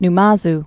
Nu·ma·zu
(n-mäz)